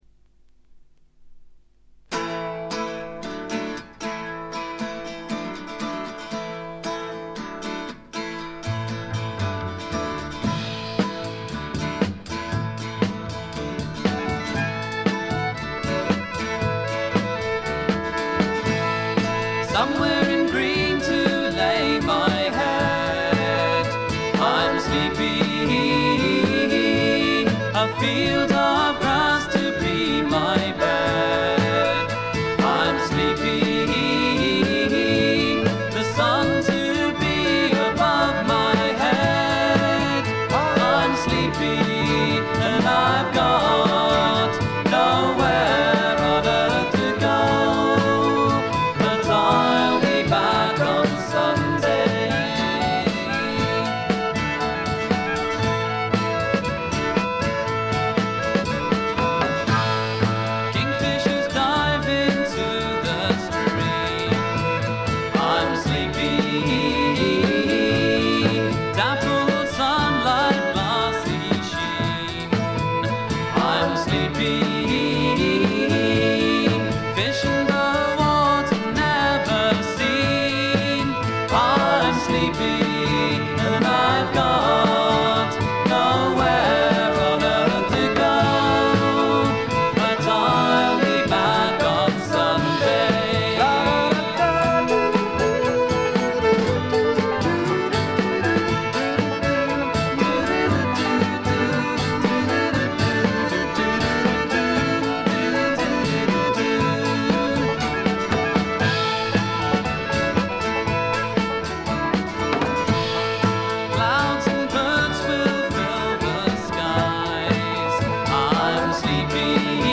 わずかなノイズ感のみ。
生きのよいフォークロックが最高ですよ。
試聴曲は現品からの取り込み音源です。